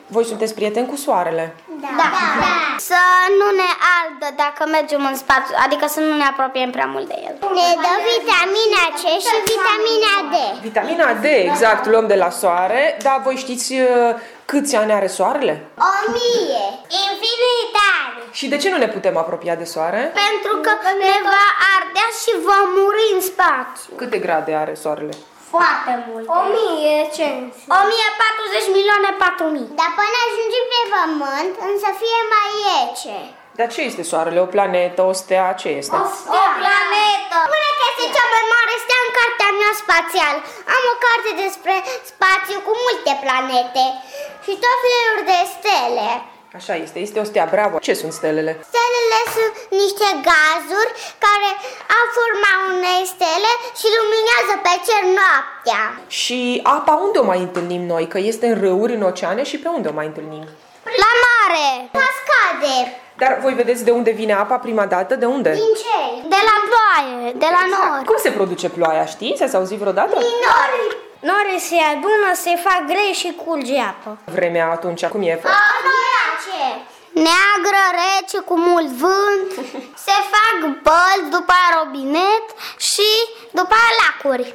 Cei mici ne explică cum apare ploaia: „norii se adună, se fac grei și curge apa”: